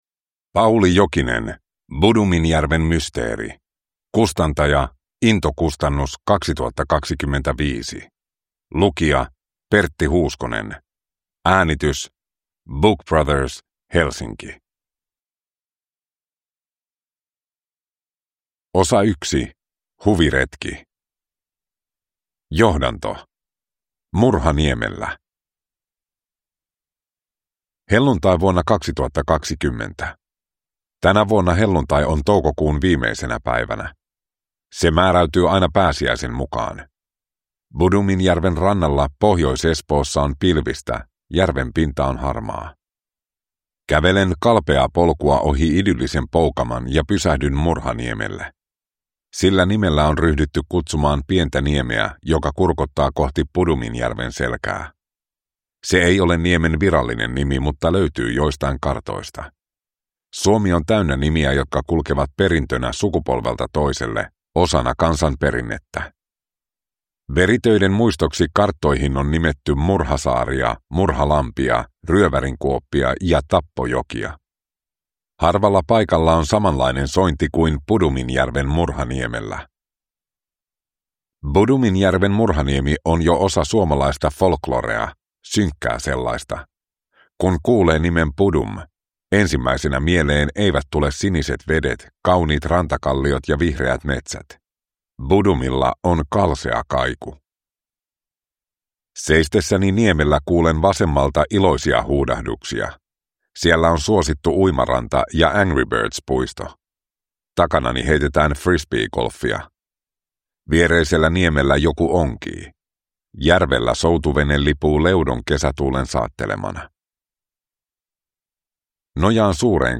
Bodominjärven mysteeri – Ljudbok